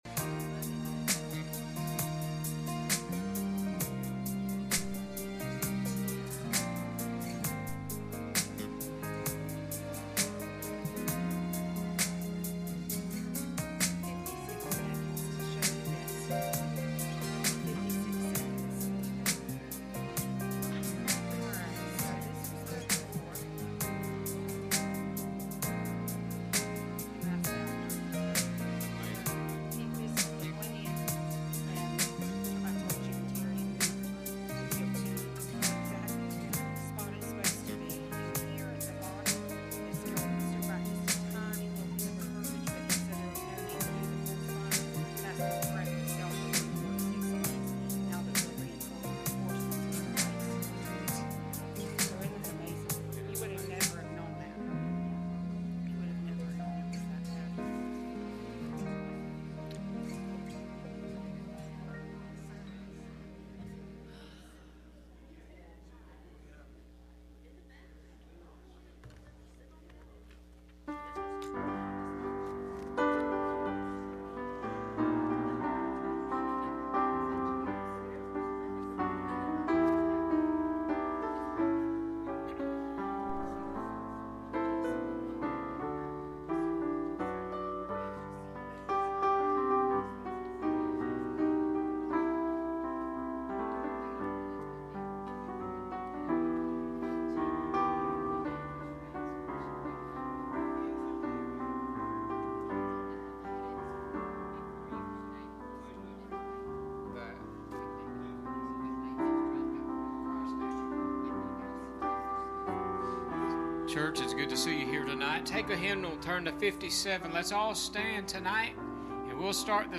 Ephesians 2:11-22 Service Type: Sunday Evening « Run To The Roar